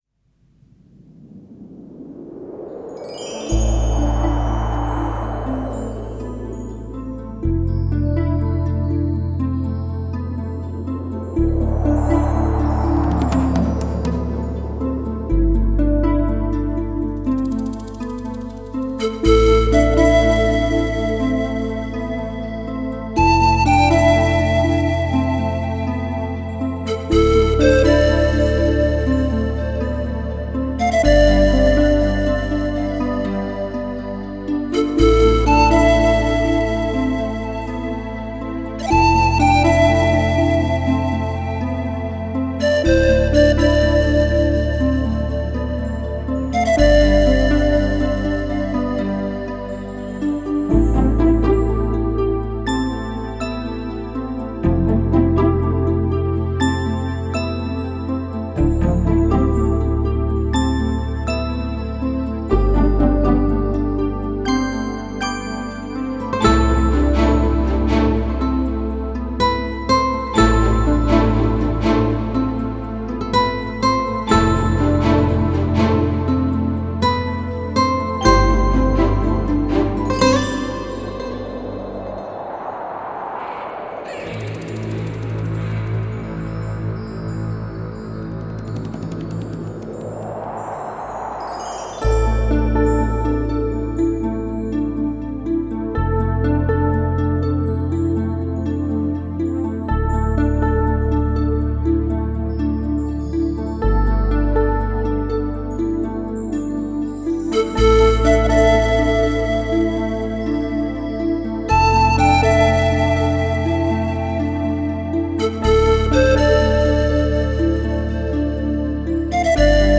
专辑语言：轻音乐
空灵飘渺编曲